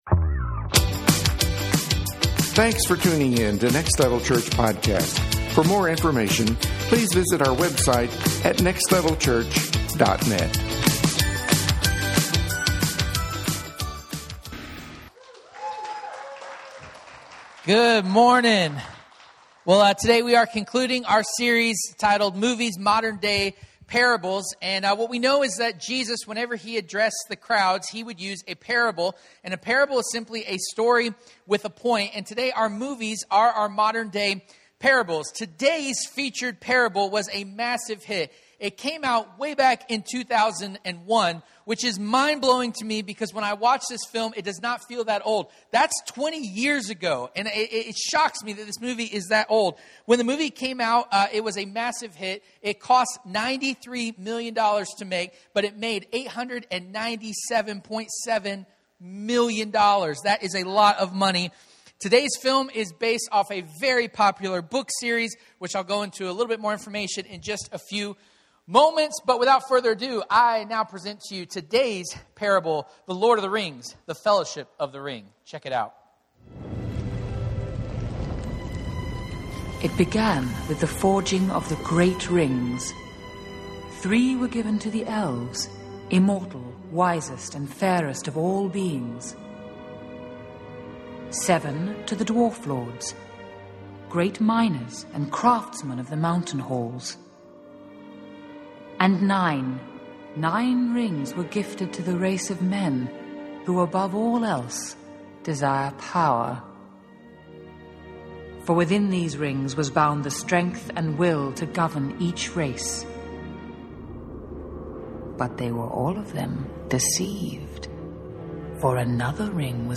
Modern Day Parables 2021 Service Type: Sunday Morning Watch « Movies